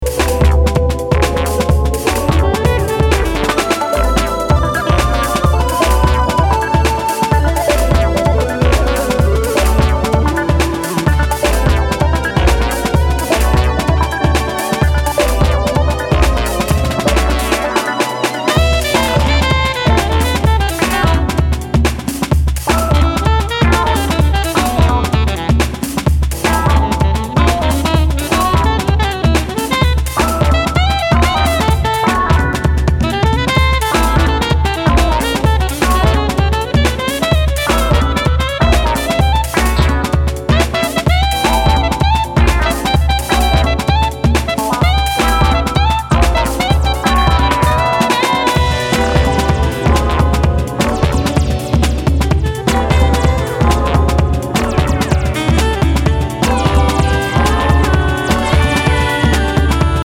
ブロークンビーツ的なドラミングにファンキーなベース・ライン
開放的な空気感を纏った爽やかで抜けのあるジャズファンク・ハウス〜ダウンテンポを繰り広げています。